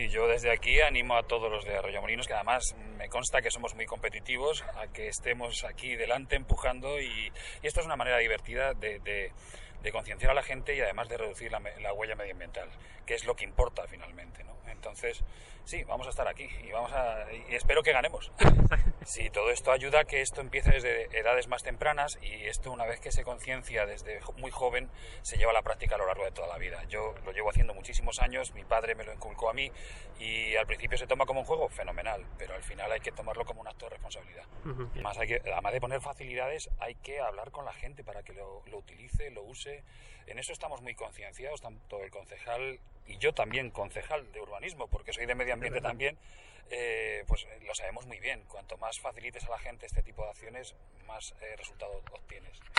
Aquí podemos escuchar las declaraciones del Alcalde de Arroyomolinos, Andrés Martínez